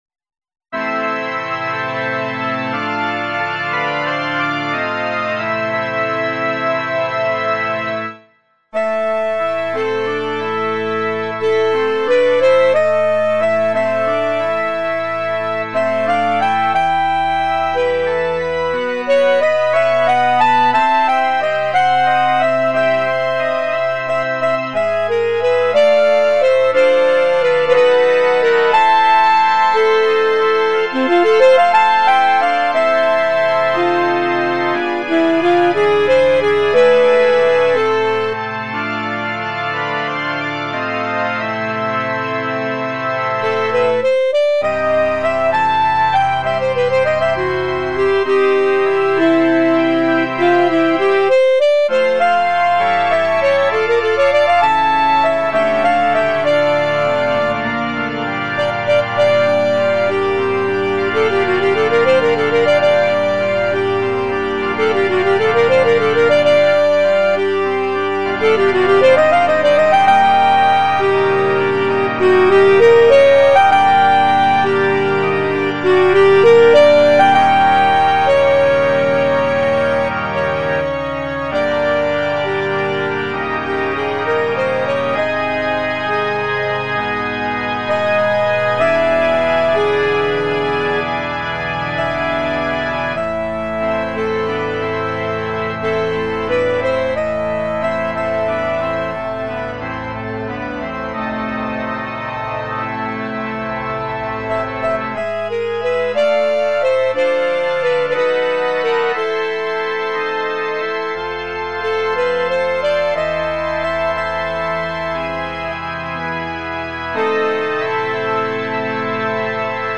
Besetzung: Instrumentalnoten für Saxophon